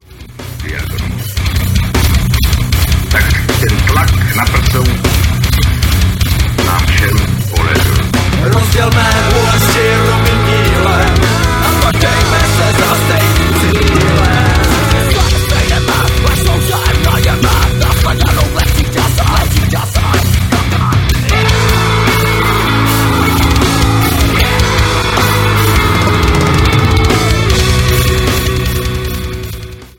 v originálním podání dnešních tvrdých kapel